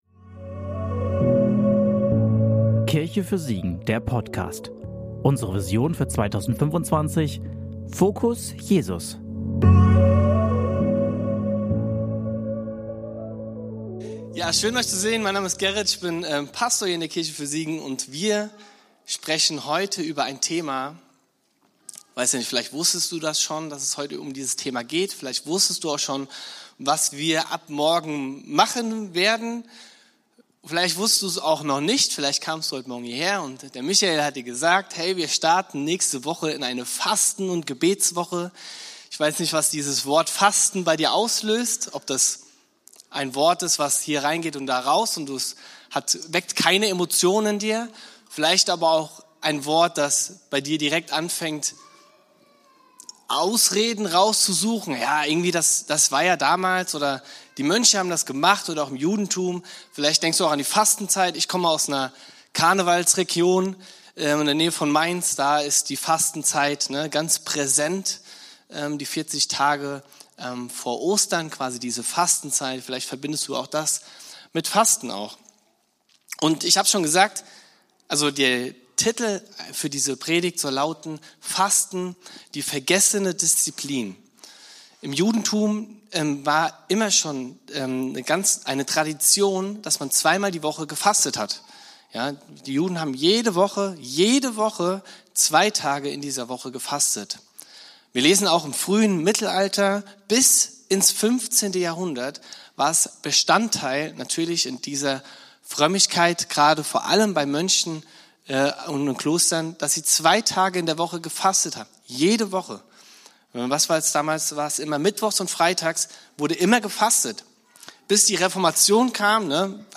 Predigt vom 01.02.2026 in der Kirche für Siegen